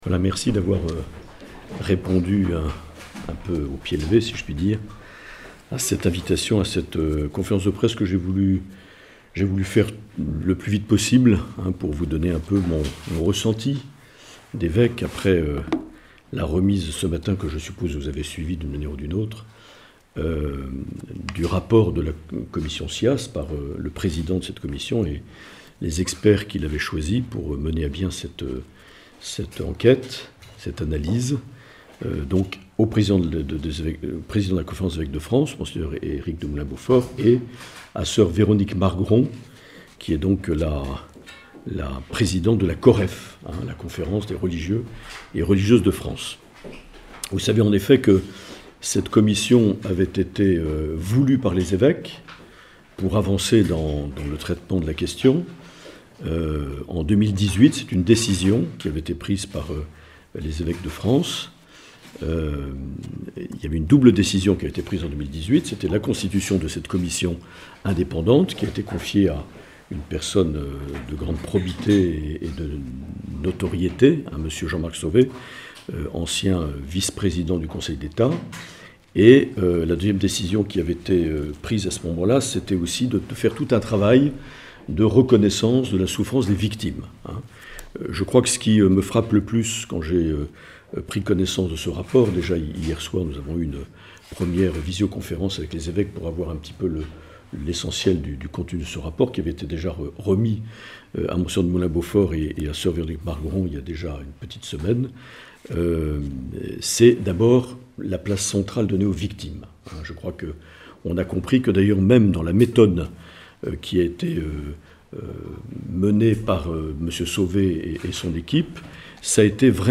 Enregistrement de la onférence de presse qui s’est tenue à l’évêché de Bayonne le mardi 5 octobre 2021 concernant la publication du rapport de Commission indépendante sur les abus sexuels dans l’Église (CIASE) présidée par Jean-Marc Sauvé.